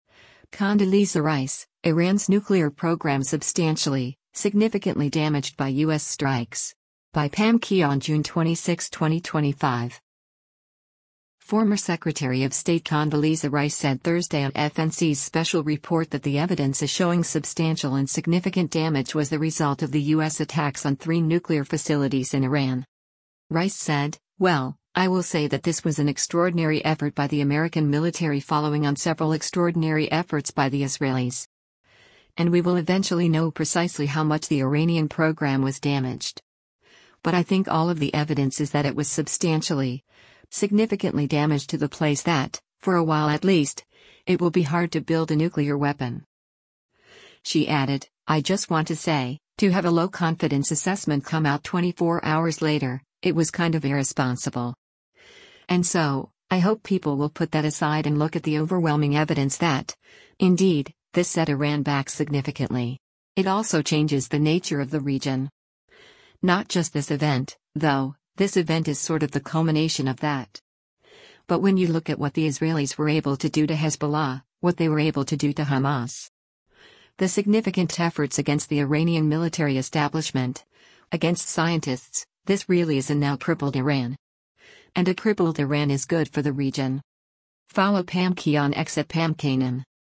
Former Secretary of State Condoleezza Rice said Thursday on FNC’s “Special Report” that the evidence is showing substantial and significant damage was the result of the U.S. attacks on three nuclear facilities in Iran.